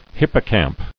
[hip·po·camp]